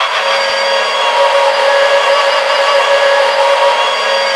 rr3-assets/files/.depot/audio/sfx/transmission_whine/tw_onlow.wav